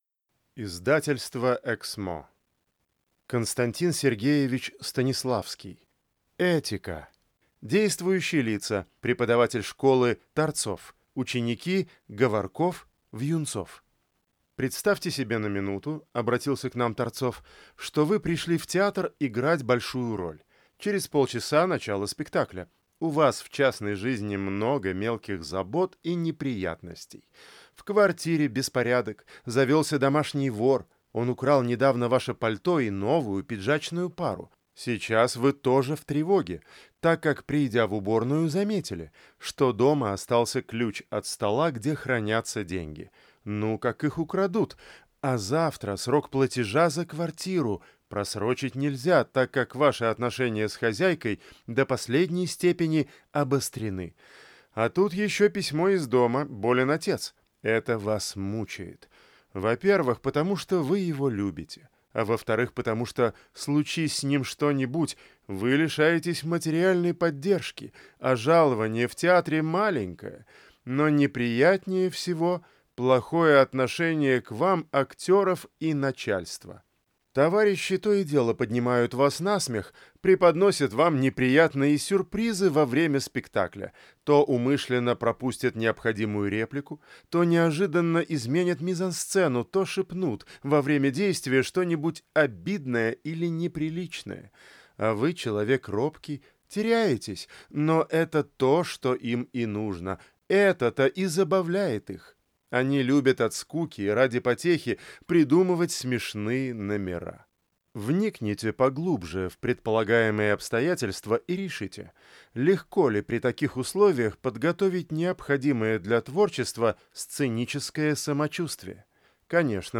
Aудиокнига Этика